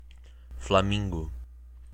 pronunciation) are gregarious wading birds in the genus Phoenicopterus and family Phoenicopteridae.